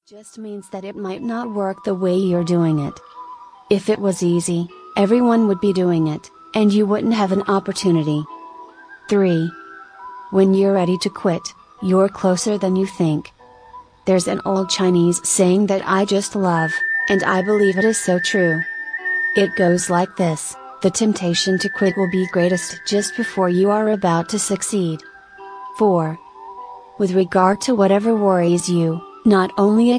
Music and thoughts on life